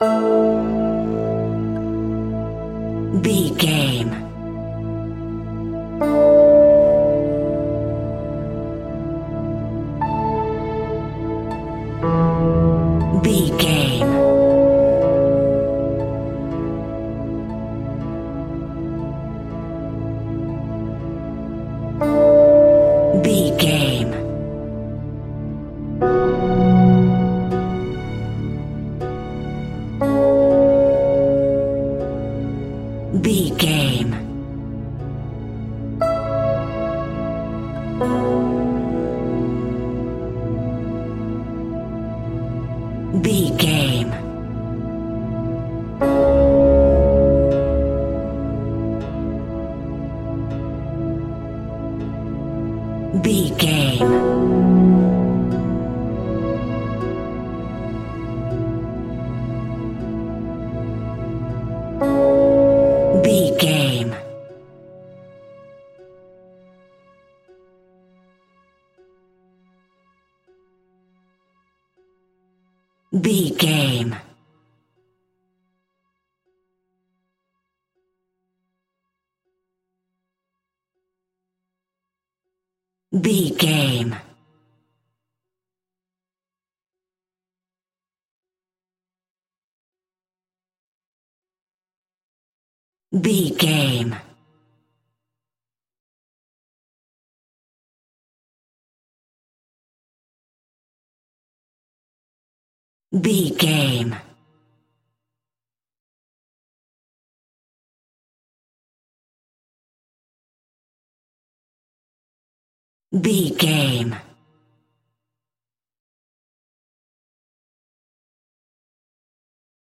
Aeolian/Minor
mystical
middle east synths
Synth Pads
ethnic percussion